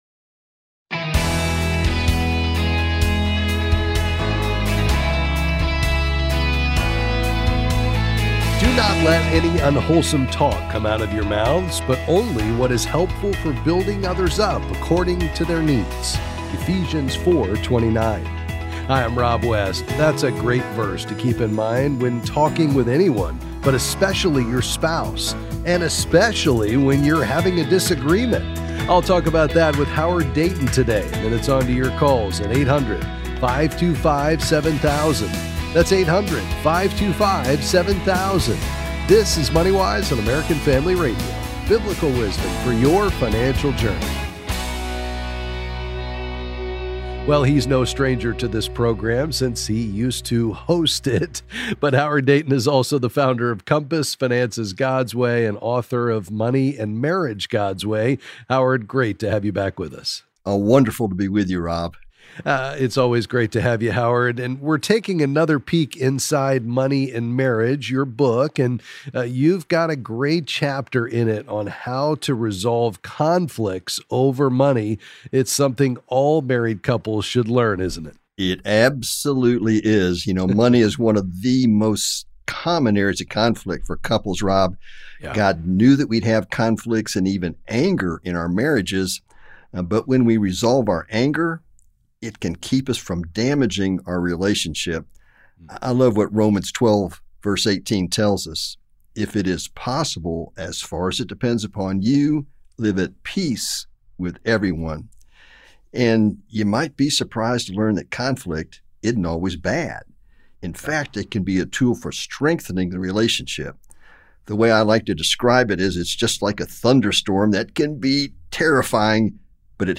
Then he’ll answer some calls and questions on a variety of financial topics.